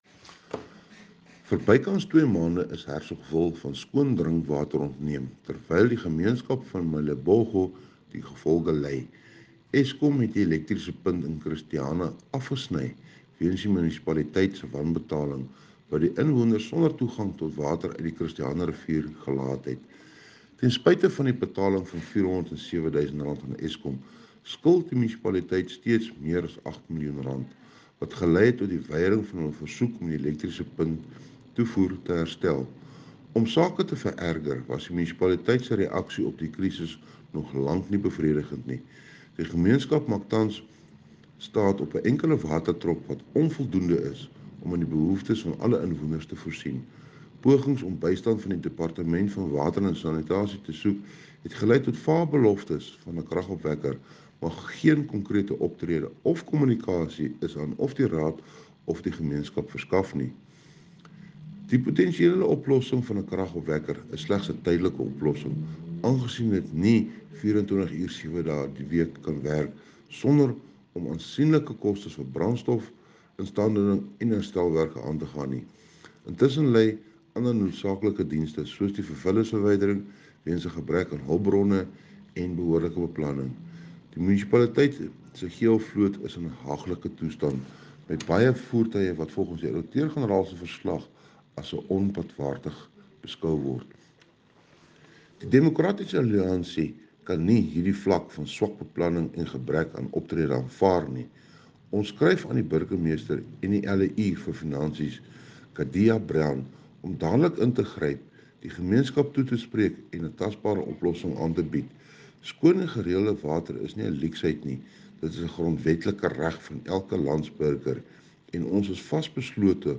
Afrikaans soundbites by Cllr Johann Steenkamp and